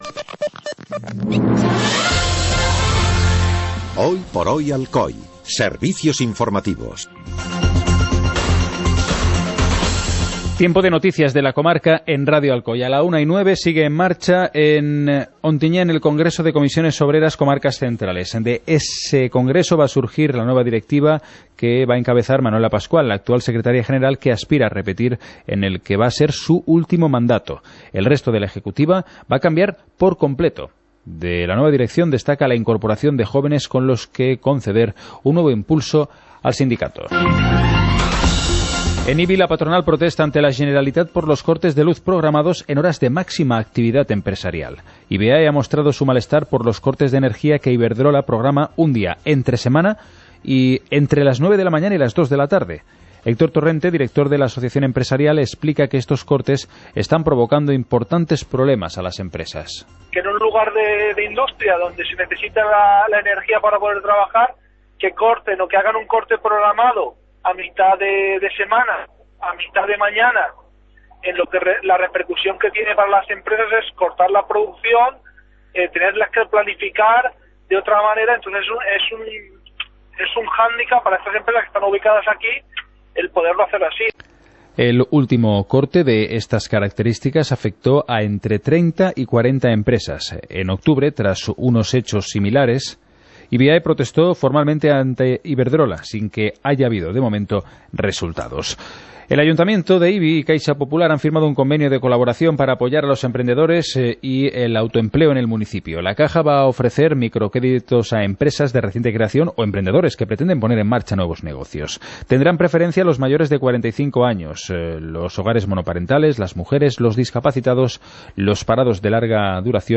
Informativo comarcal - viernes, 10 de marzo de 2017